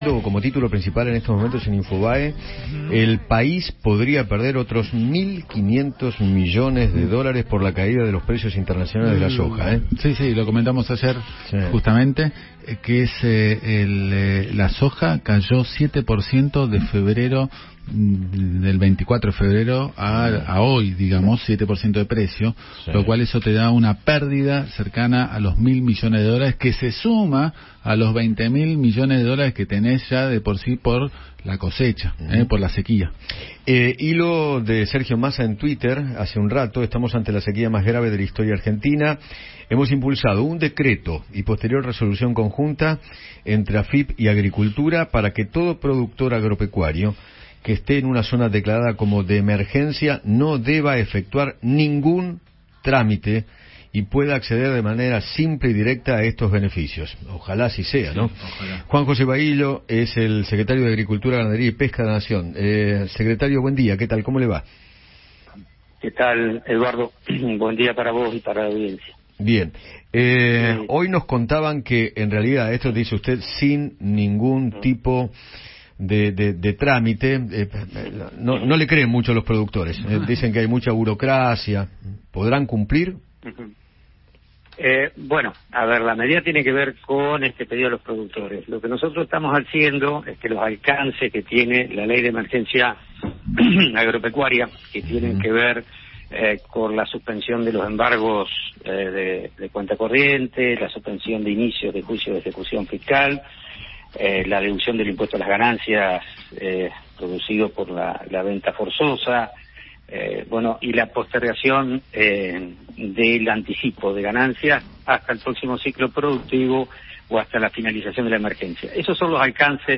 Juan José Bahillo, Secretario de Agricultura de la Nación, conversó con Eduardo Feinmann sobre las nuevas medidas de alivio fiscal para el sector.